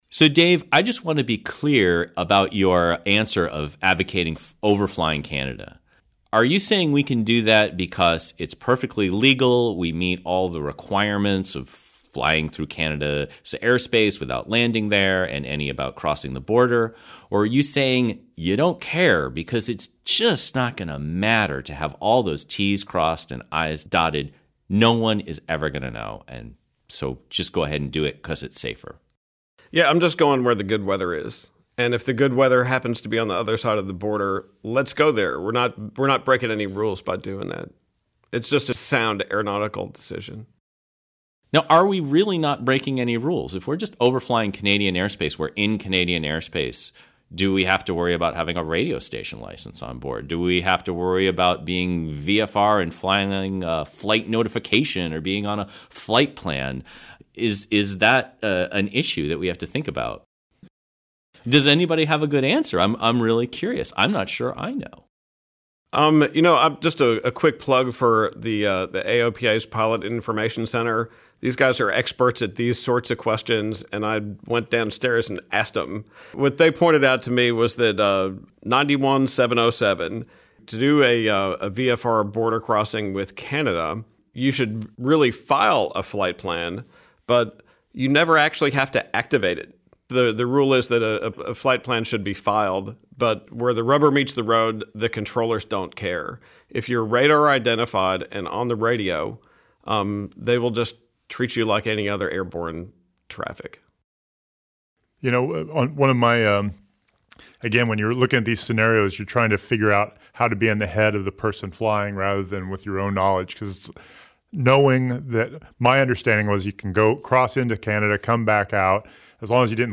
That_Erie_Feeling_roundtable.mp3